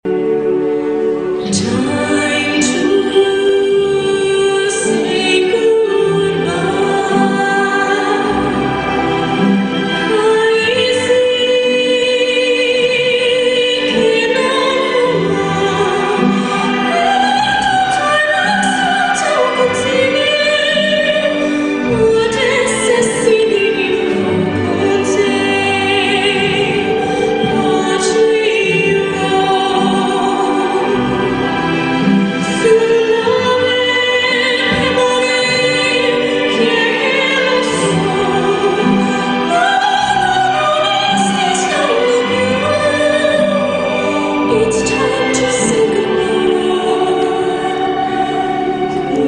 Singer, Musician